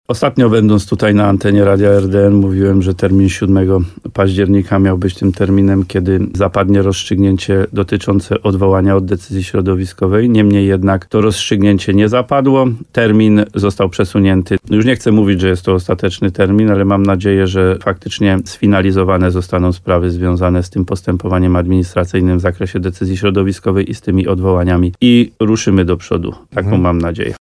Jak powiedział wójt gminy Łososina Dolna w programie Słowo za Słowo w radiu RDN Nowy Sącz, decyzja Generalnej Dyrekcji Ochrony Środowiska, uwzględniająca plan samorządów, jest bardzo oczekiwana.